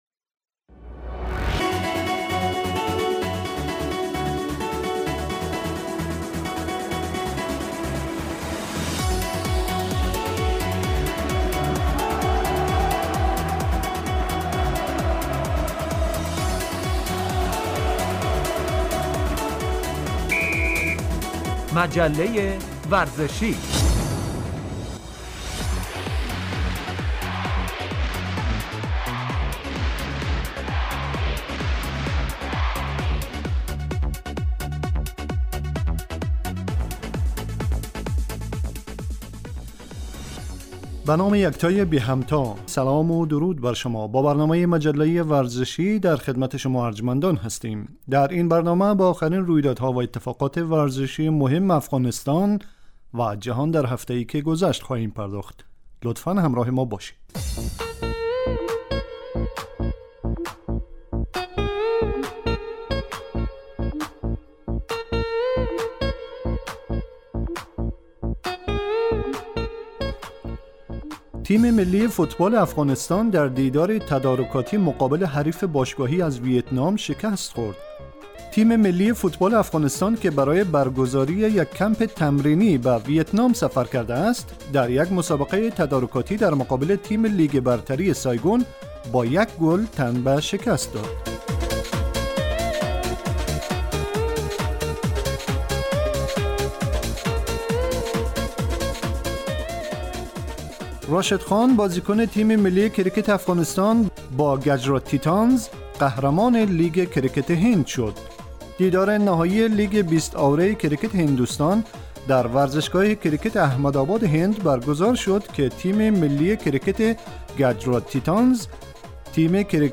آخرين اخبار و رويدادهاي ورزشي افغانستان و جهان در هفته اي که گذشت به همراه گزارش و مصاحبه وبخش ورزش وسلامتي